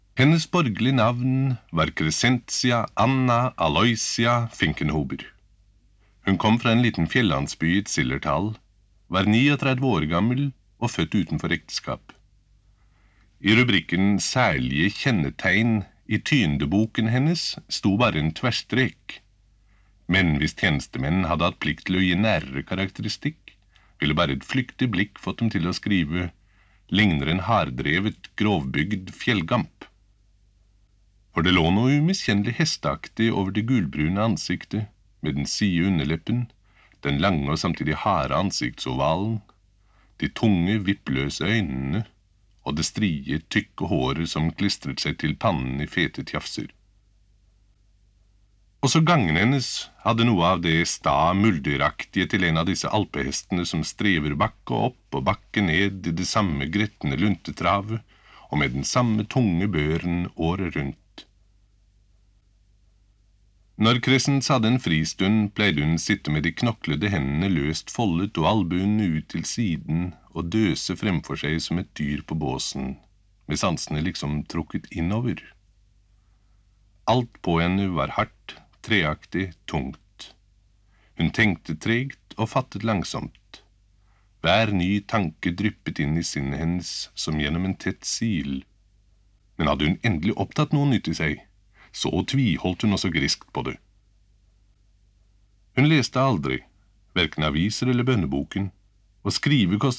Nedlastbar lydbok